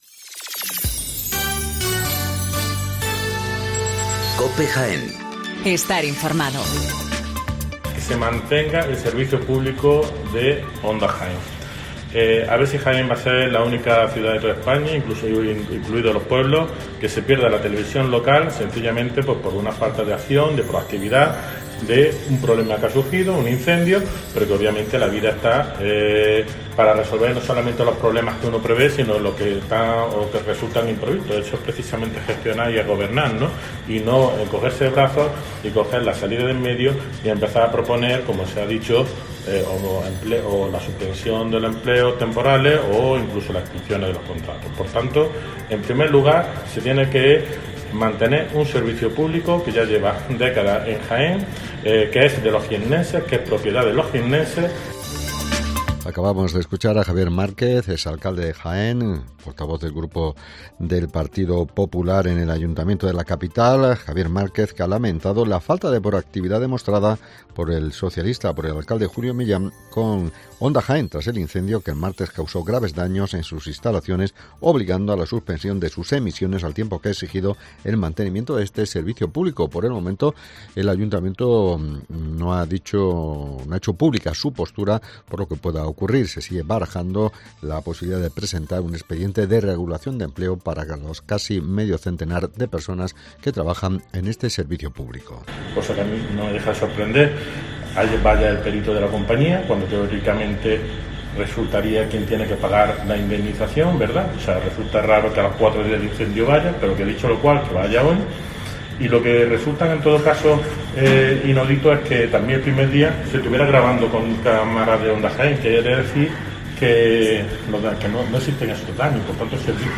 Las noticias locales
Toda la actualidad, las noticias más próximas y cercanas te las acercamos con los sonidos y las voces de todos y cada uno de sus protagonistas.